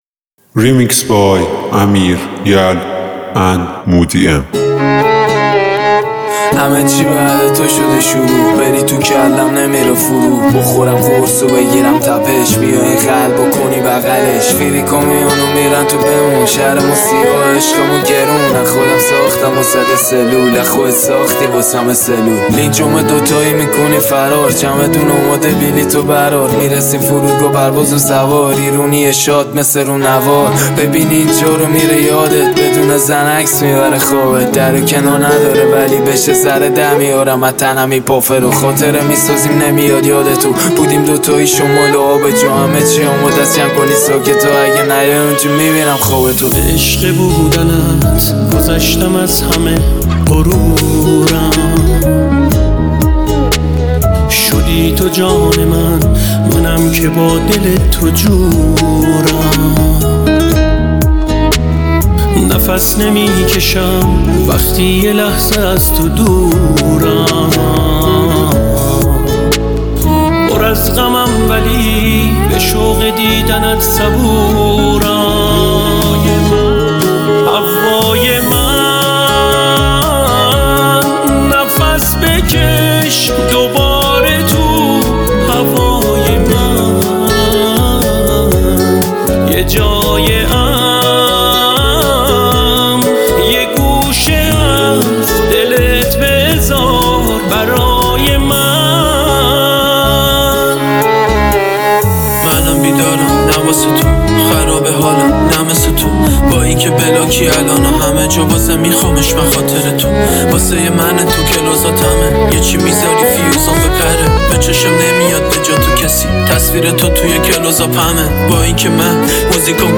(Remix)